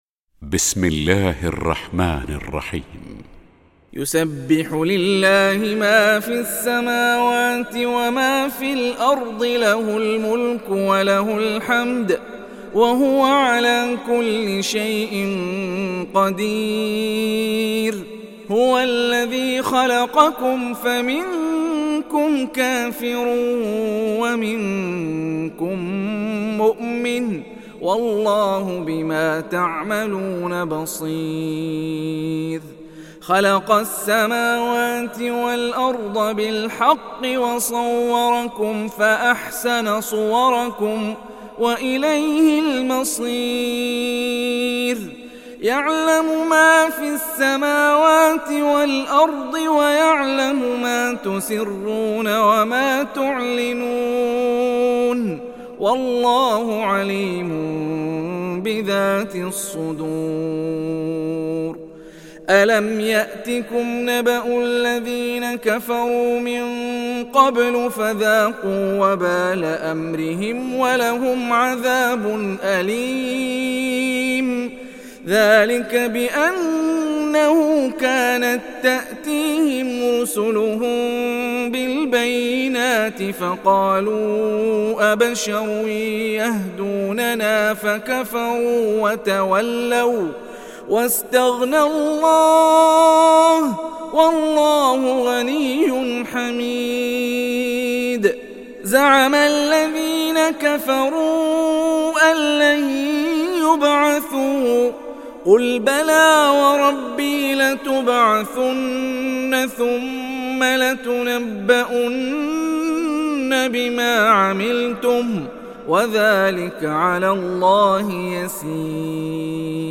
সূরা আত-তাগাবুন ডাউনলোড mp3 Hani Rifai উপন্যাস Hafs থেকে Asim, ডাউনলোড করুন এবং কুরআন শুনুন mp3 সম্পূর্ণ সরাসরি লিঙ্ক